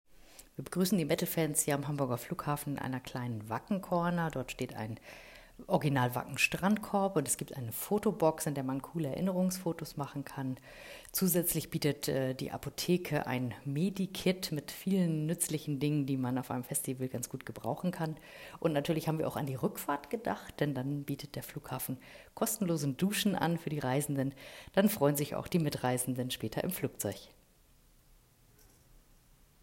Audio Statement